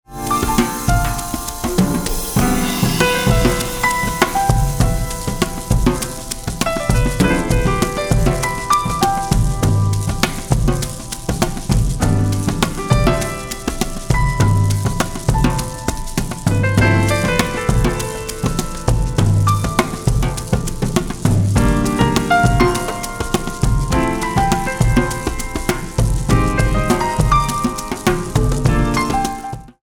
piano and percussion music